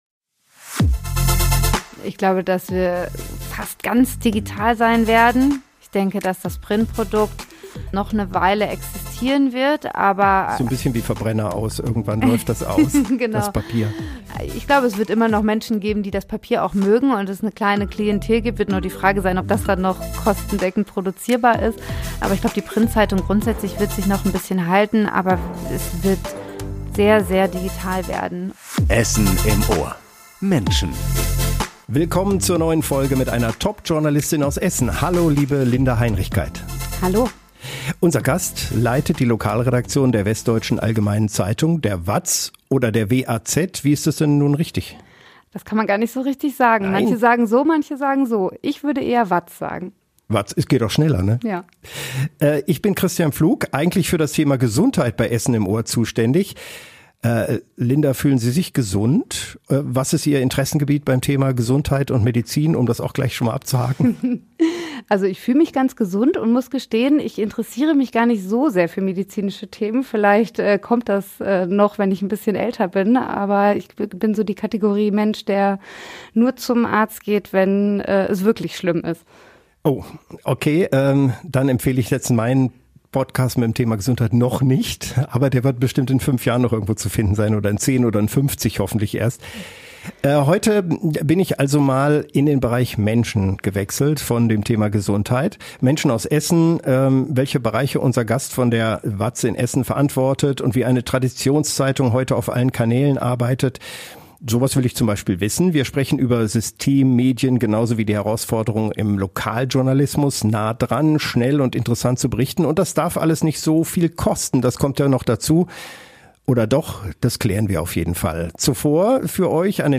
Ihre überstrahlende Eigenschaft im Interview: Optimismus.